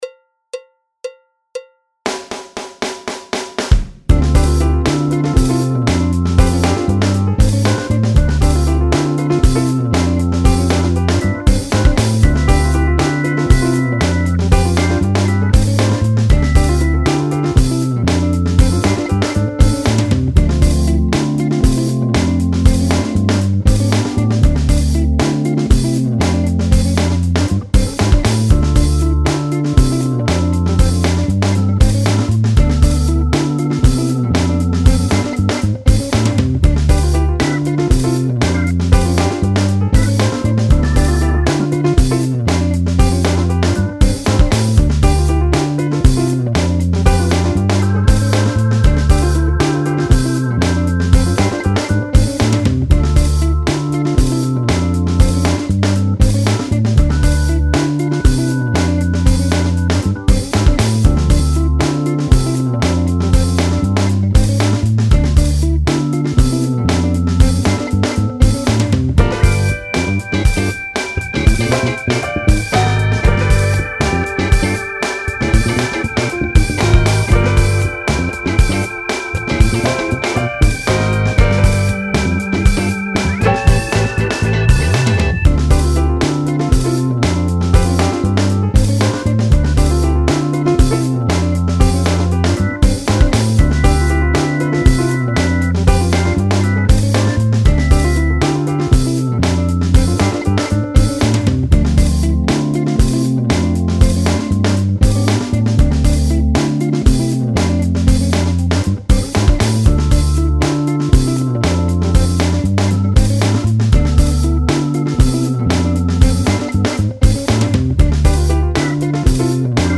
4/4 (View more 4/4 Music)
Drums  (View more Advanced Drums Music)
Pop (View more Pop Drums Music)